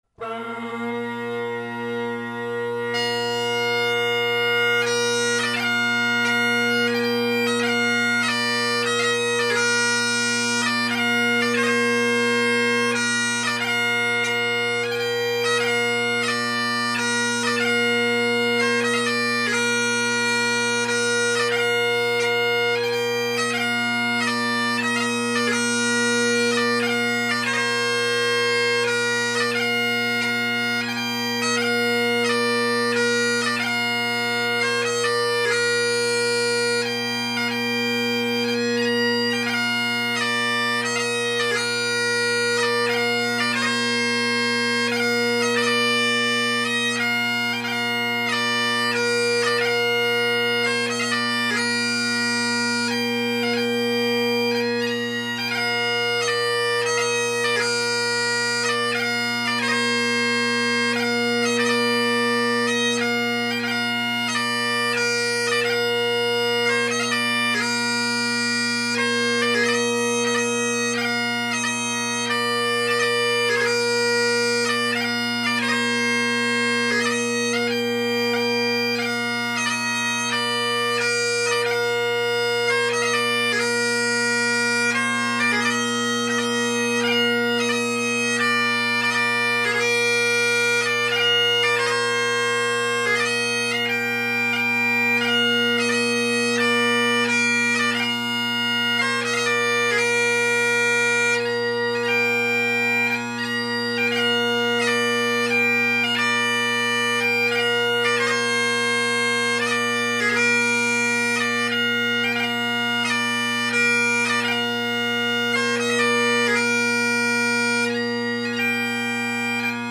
Drone Sounds of the GHB, Great Highland Bagpipe Solo
Also, the reeds just wouldn’t settle.
Selbie’s took forever to tune because of big overtone amplitudes.